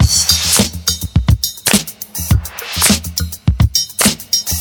• 104 Bpm Modern Rap Breakbeat D# Key.wav
Free breakbeat sample - kick tuned to the D# note. Loudest frequency: 3886Hz
104-bpm-modern-rap-breakbeat-d-sharp-key-rXN.wav